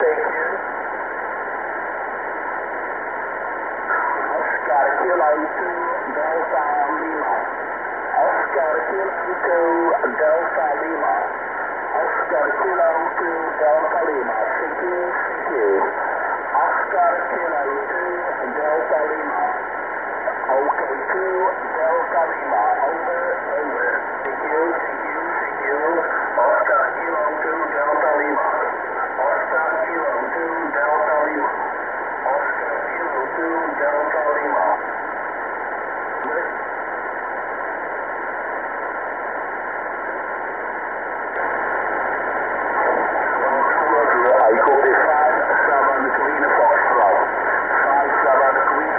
Zaraz postaram się przesłać kilka nagrań sygnałów SSB.